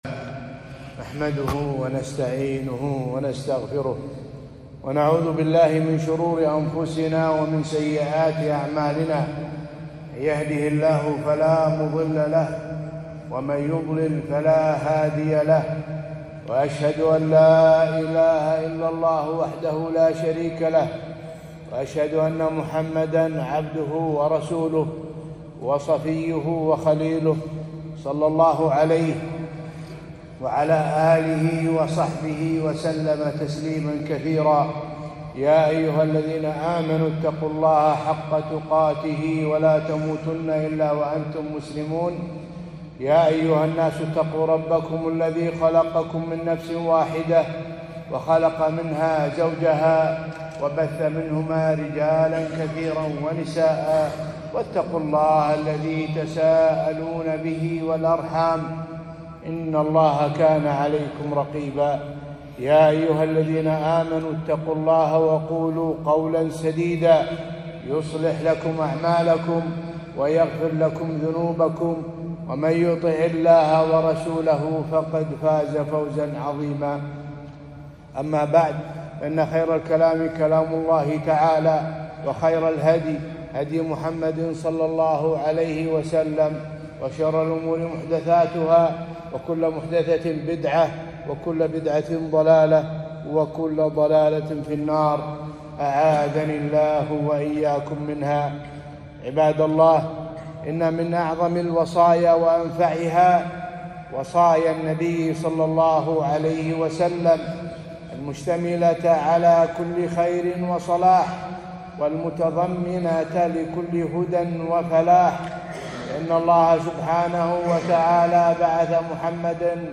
خطبة - أوصاني خليلي ﷺ